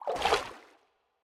Minecraft Version Minecraft Version snapshot Latest Release | Latest Snapshot snapshot / assets / minecraft / sounds / mob / turtle / swim / swim1.ogg Compare With Compare With Latest Release | Latest Snapshot
swim1.ogg